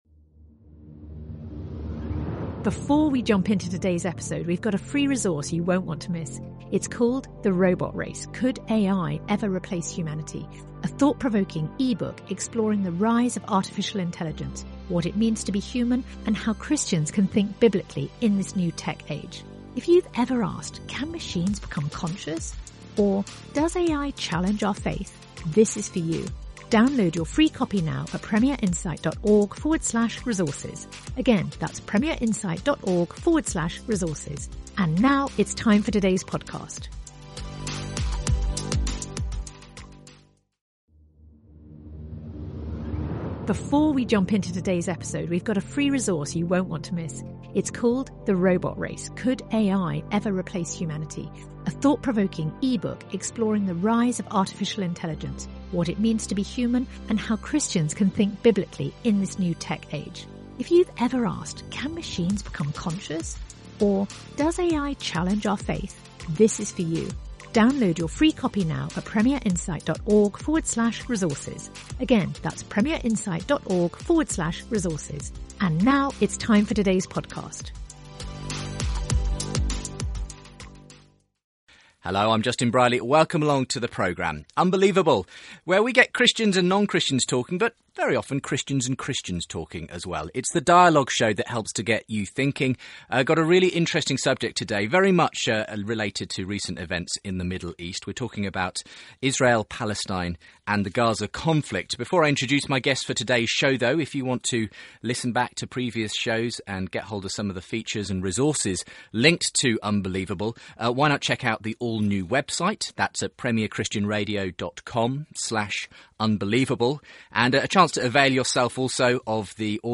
Christianity, Religion & Spirituality 4.6 • 2.3K Ratings 🗓 15 August 2014 ⏱ 82 minutes 🔗 Recording | iTunes | RSS 🧾 Download transcript Summary There have been thousands of casualties in Gaza this summer as Israel's military have responded to Hamas rocket attacks. A Messianic Jew and a Palestinian Christian